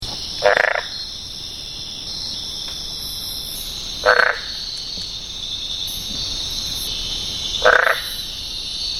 Common names: Blue-spotted Treefrog
Call is one or two short notes, "wonk, wonk".
S_cyanosticta.mp3